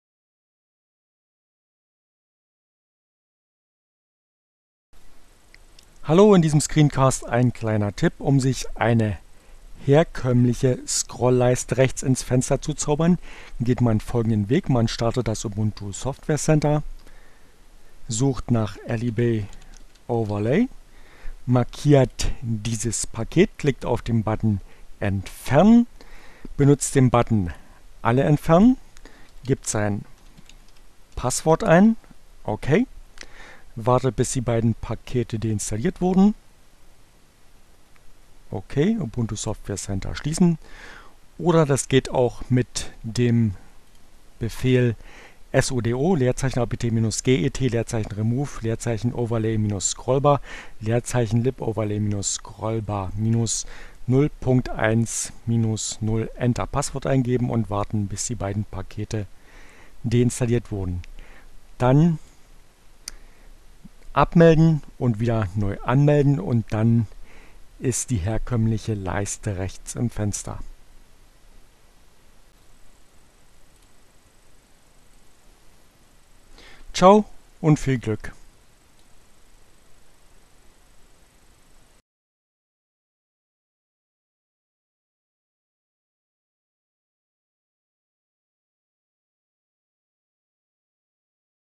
Tags: CC by-sa, Linux, Neueinsteiger, Ogg Theora, ohne Musik, screencast, ubuntu, Unity, Scrollleiste